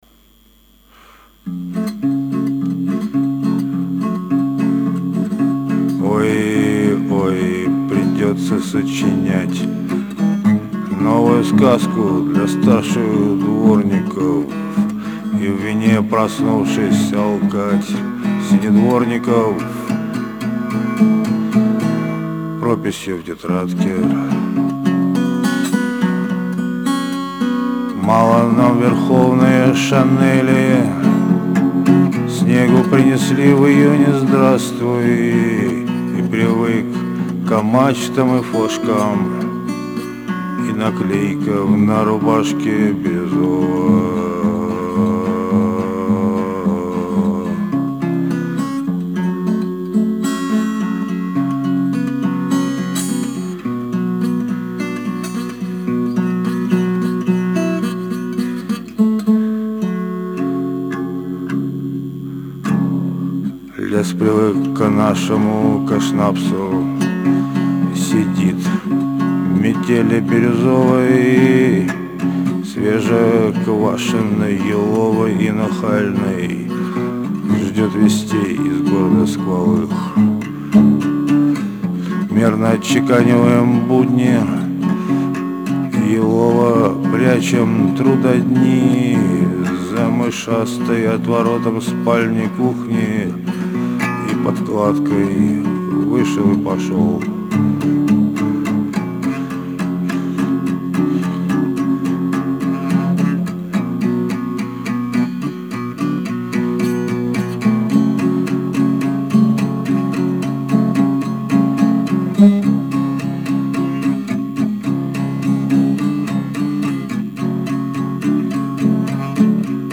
напел с**час стихо что сегодня соч--- ---- ---------- -- 22июня